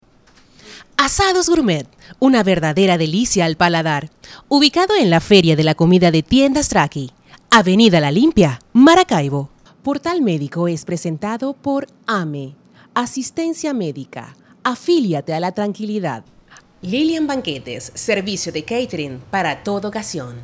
Locutora profesional, voz marca, voz institucional, productora, narradora de noticias.
Sprechprobe: Sonstiges (Muttersprache):